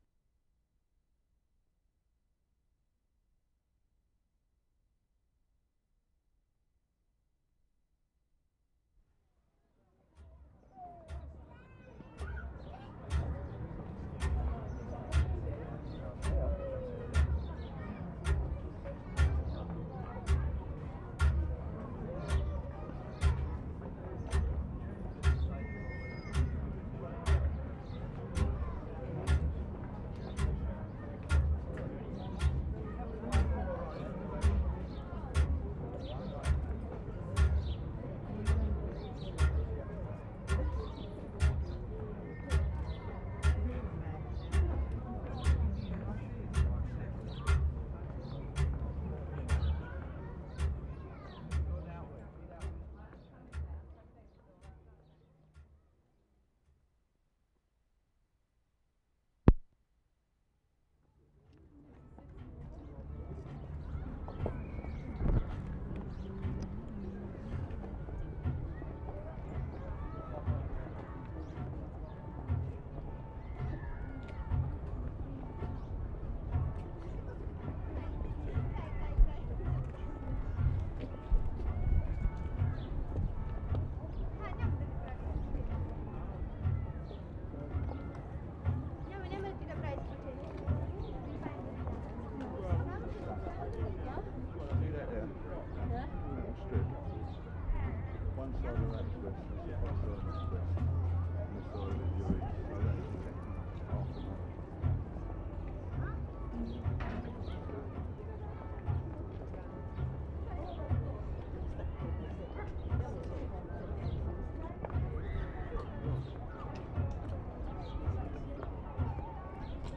WORLD SOUNDSCAPE PROJECT TAPE LIBRARY
GREENWICH GATE CLOCK
6. & 7. Outdoor ambience of people, birds in combination with regular ticking of clock.